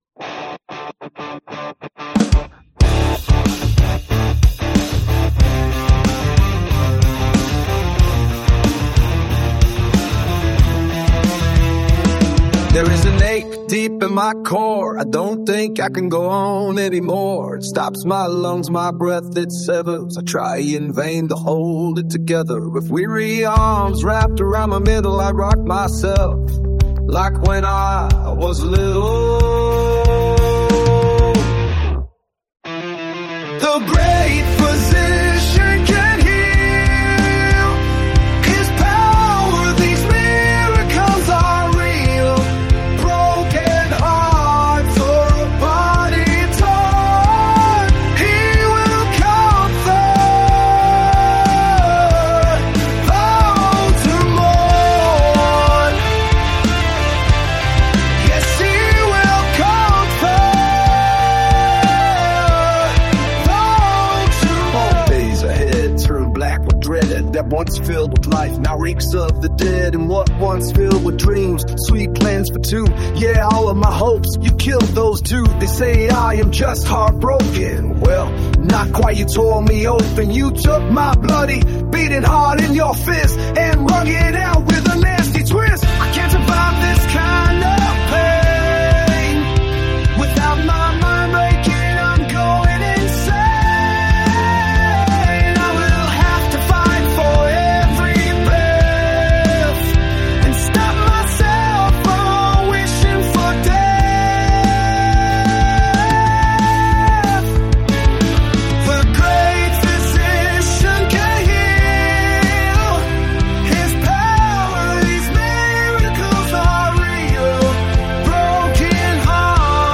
Poetry set to Heavy Rock!